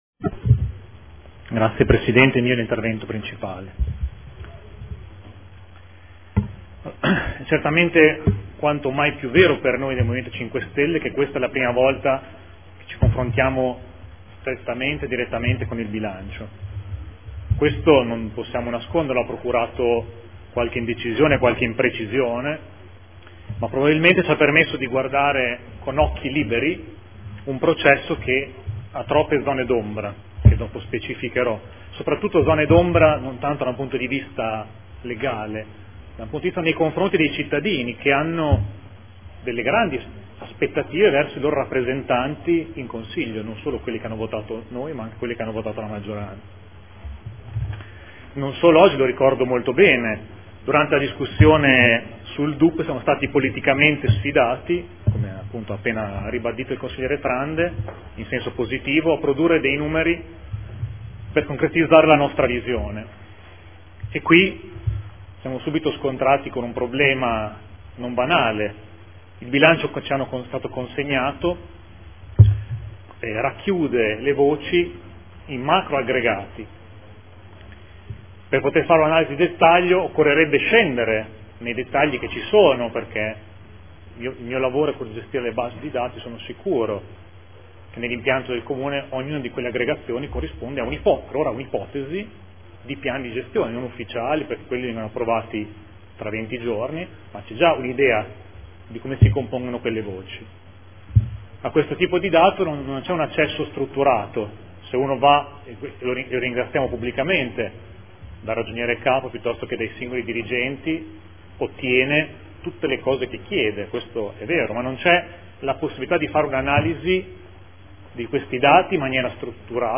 Seduta del 05/03/2015 Dibattito sul Bilancio, sulle delibere, odg ed emendamenti collegati